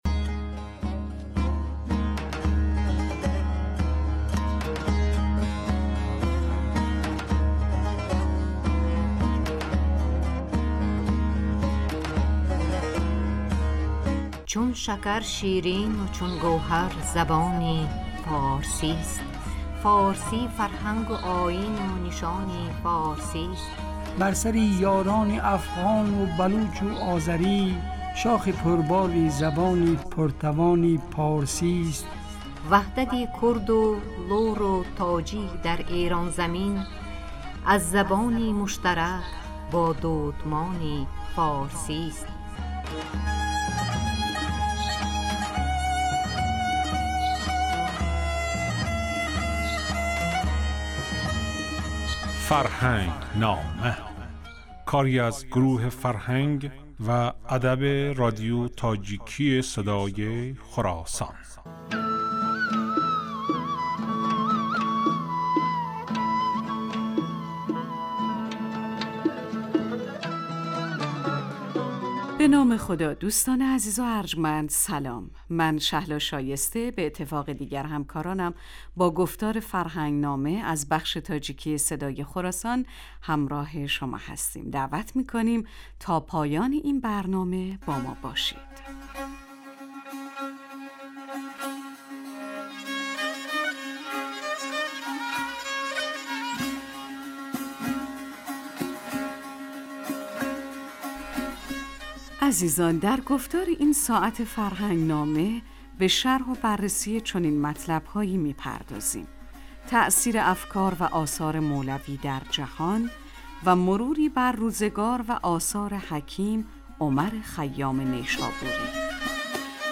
Ин гуфтор ҳар ҳафта рӯзи сешанбе, дар бахши субҳгоҳӣ ва шомгоҳӣ аз Садои Хуросон пахш мегардад.